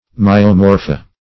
Myomorpha \My`o*mor"pha\, n. pl. [NL., fr. Gr. my^s, myo`s, a